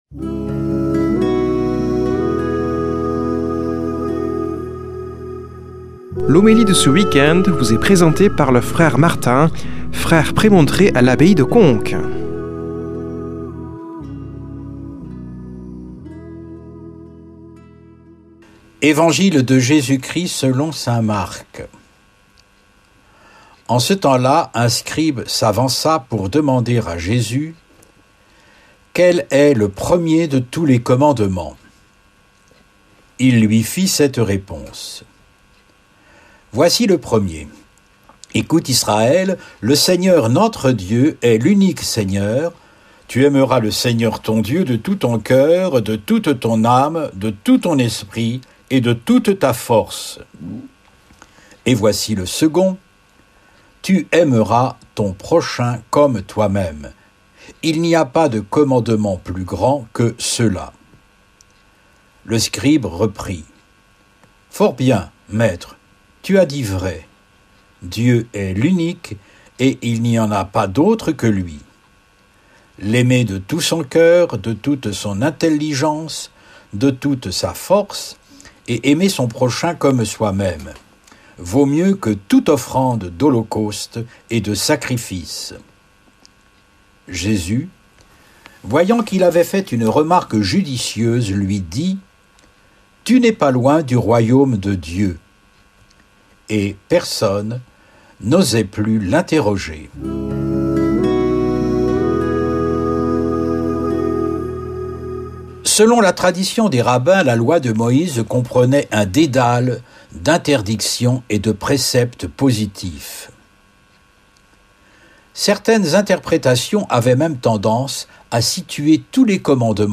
Homélie du 26 oct.
Présentateur